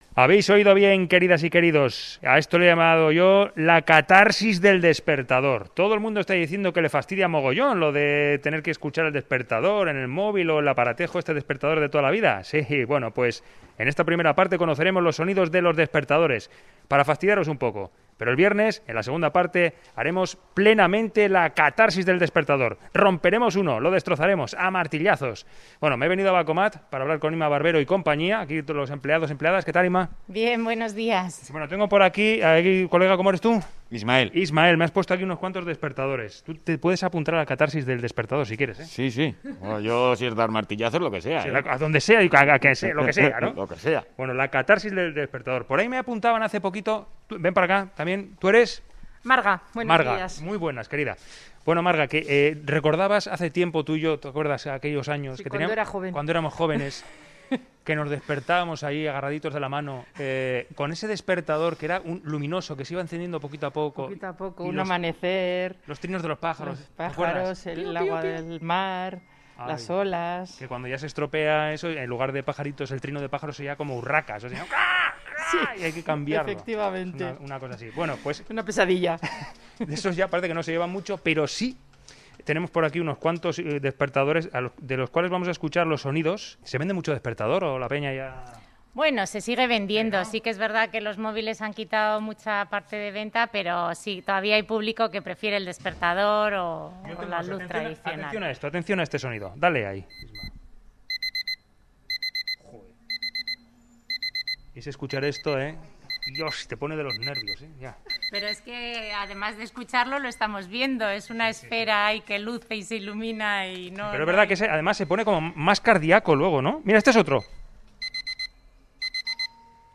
Audio: Reportaje: La catarsis del despertador (parte 1)